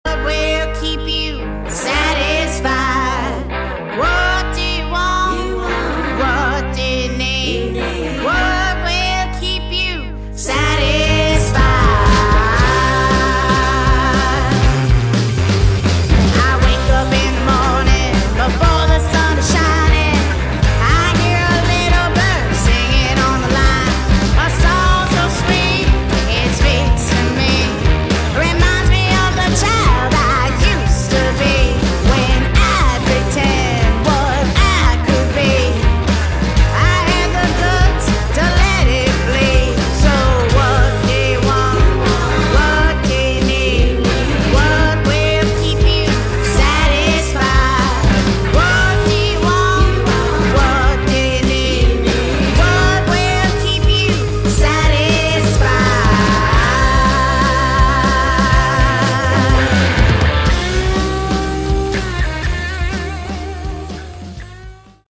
Category: Punk/Sleaze/Garage Rock
lead vocals